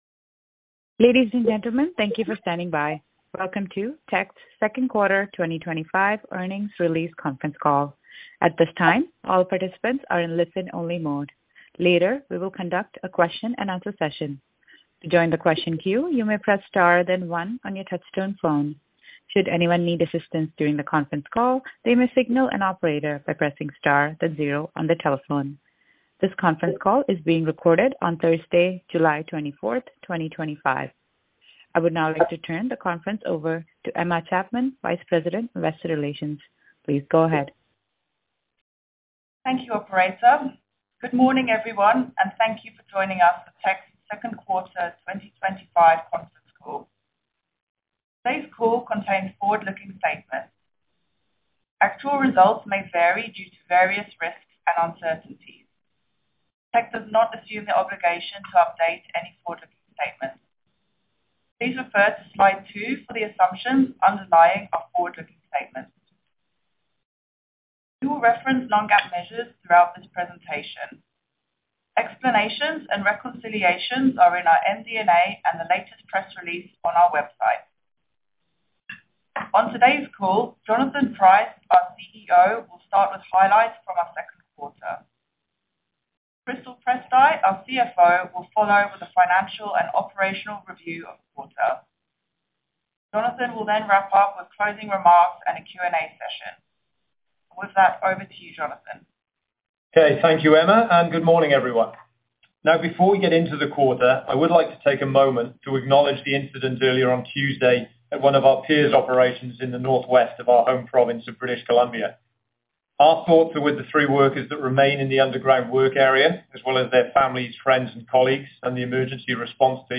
Q2 2025 Financial Report [PDF - 0.54 MB] Q2 2025 Presentation [PDF - 2.31 MB] Q2 2025 Presentation Appendix [PDF - 6.85 MB] Q2 2025 Conference Call Audio [MP3 - 24.13 MB] Q2 2025 Conference Call Transcript [PDF - 0.17 MB]